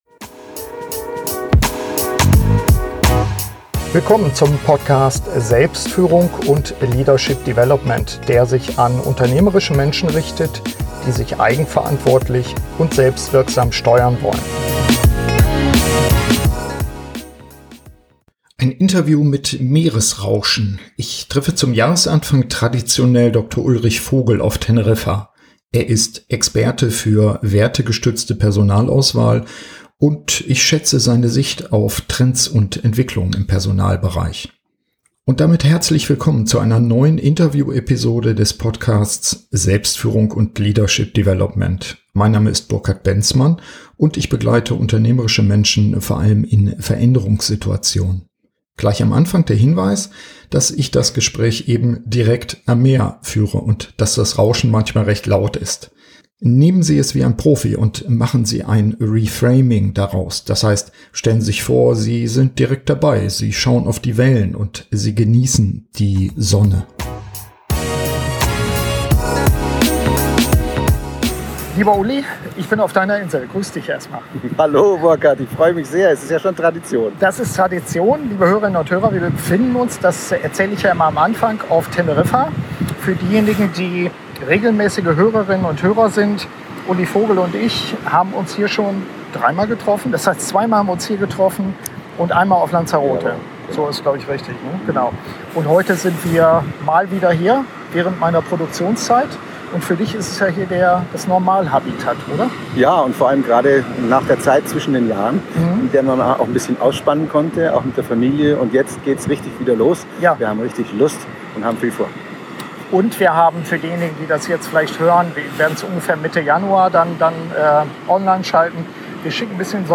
SF123 Die richtigen Mitarbeiter finden - Interview